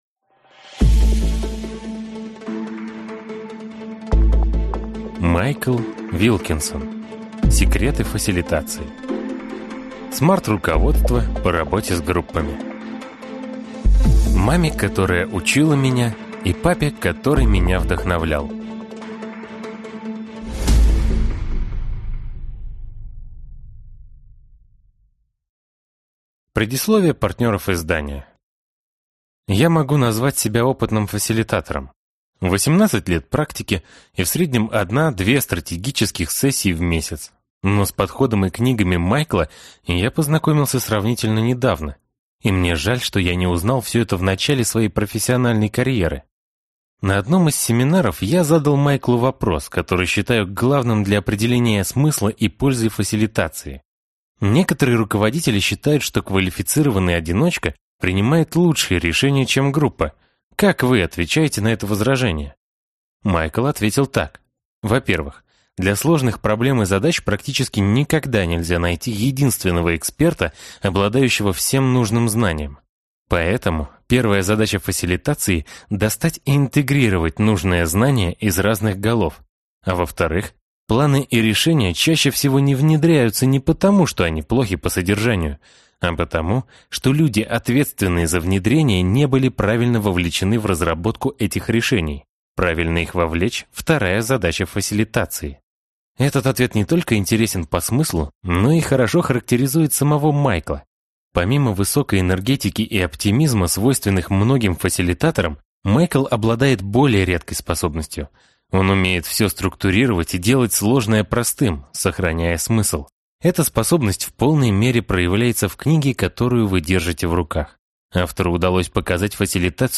Аудиокнига Секреты фасилитации. SMART-руководство по работе с группами | Библиотека аудиокниг